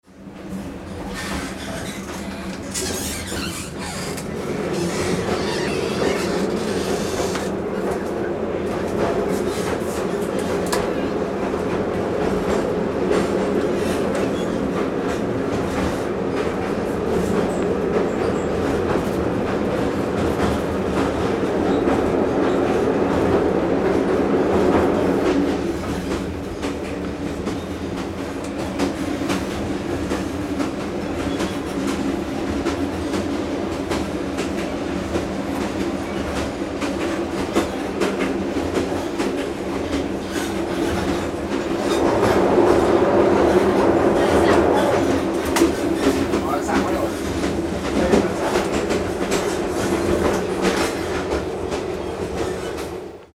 Gemafreie Sounds: Bahn - Alte Züge
mf_SE-8195-old_train_cabin_inside_asia.mp3